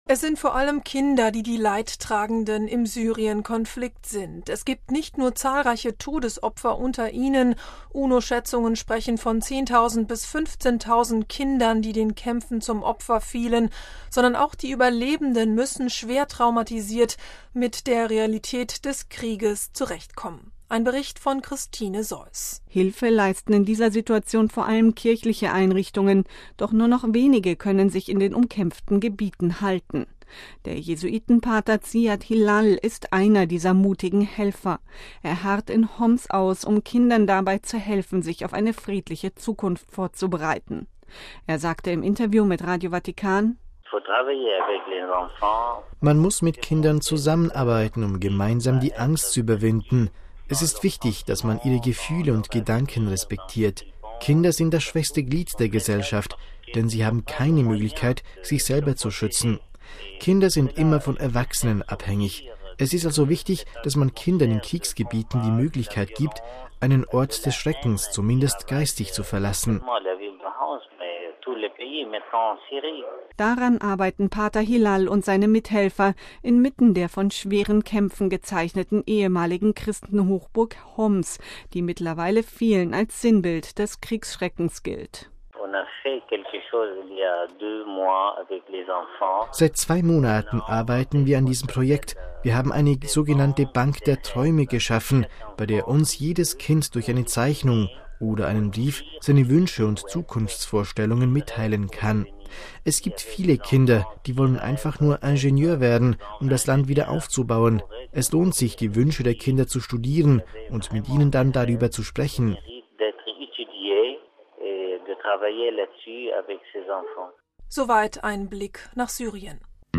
Er sagte im Interview mit Radio Vatikan: